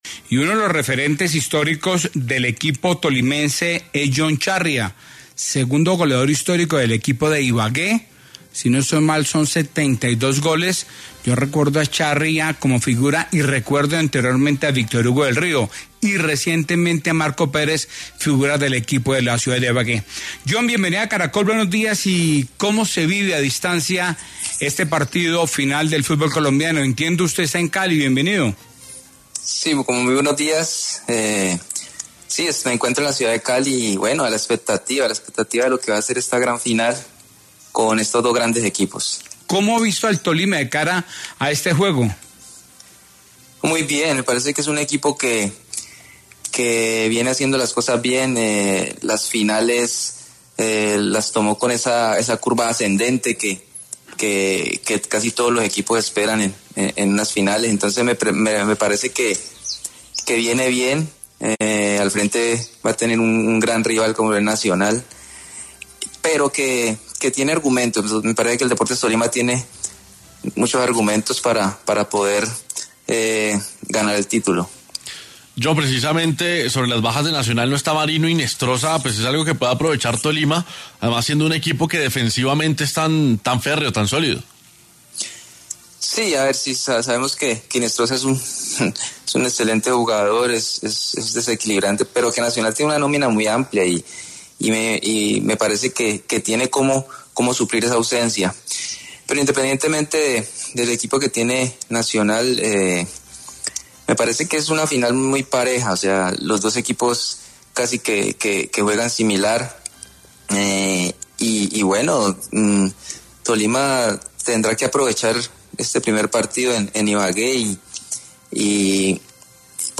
El ex jugador de Deportes Tolima y Atlético Nacional estuvo en el noticiero ‘6AM’ de Caracol Radio y palpitó la gran final del FPC.